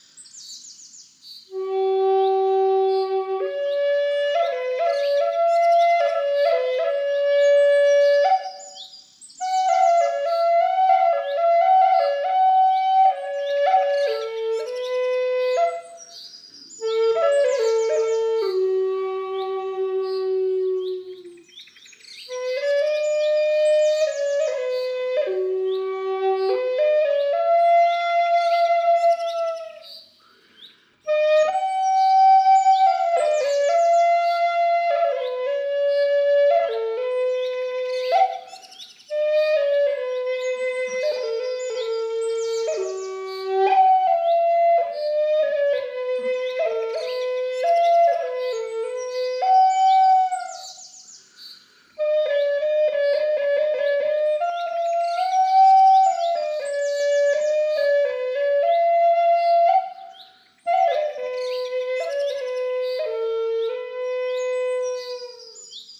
Lakota flute in key of G (sol) – Avaye Lotus
Lakota-key-sol-middle.mp3